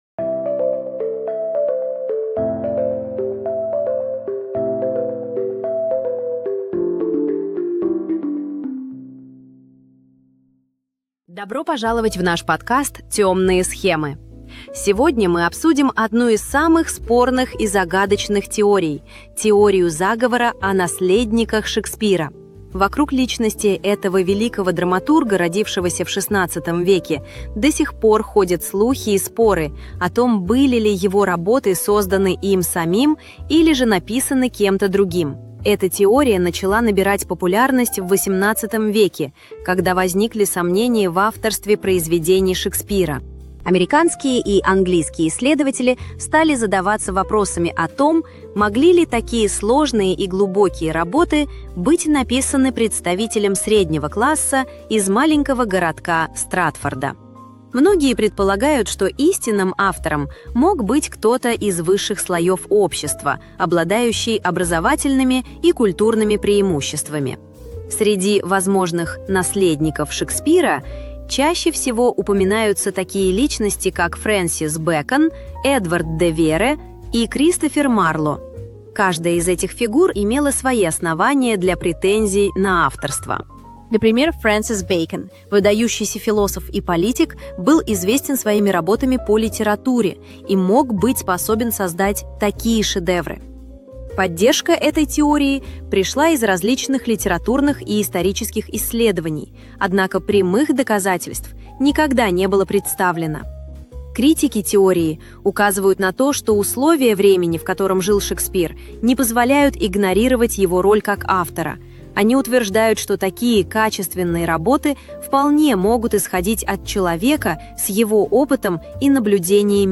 • TTS-сервисы - ElevenLabs, SaluteSpeech, локальный Piper TTS
При финальной генерации к подкасту добавляются пользовательские интро, аутро и фоновая музыка.